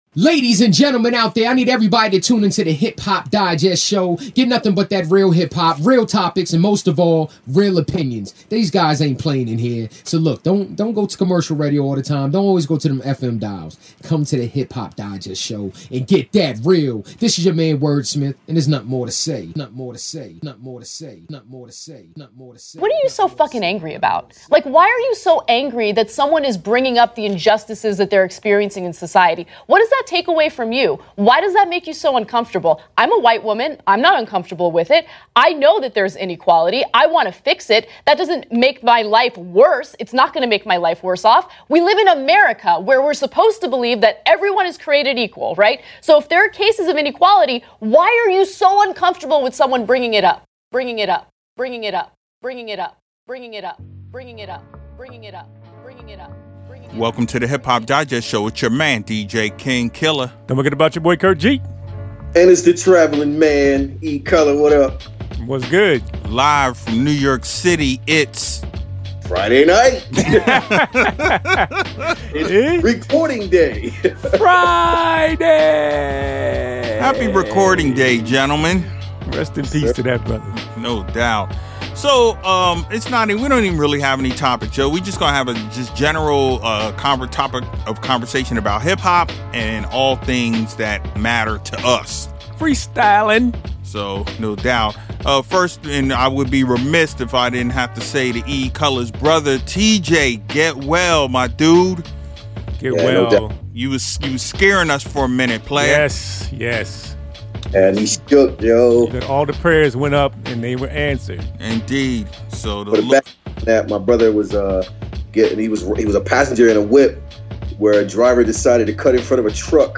On this episode, we bust some freestyles! No topics, just your dudes having a conversation about whatever comes to mind.